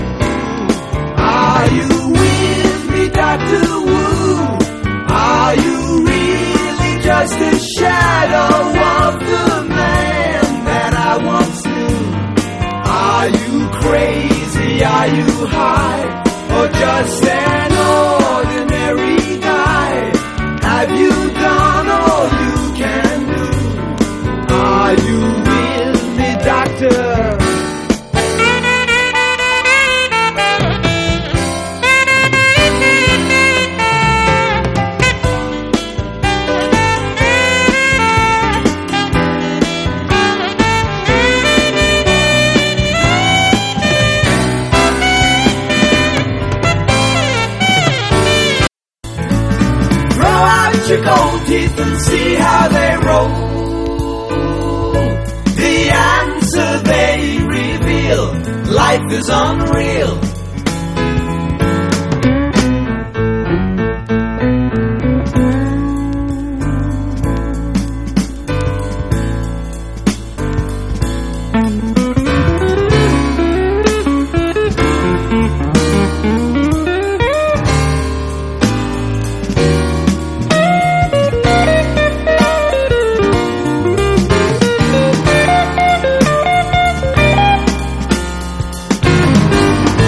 ROCK / S.S.W./A.O.R.
スライド・ギターが唸りまくるニューオリンズ風いなためファンキー・グルーヴ
ポジティヴなホーンやハーモニーがジューシーに包み込むサンシャインA.O.R.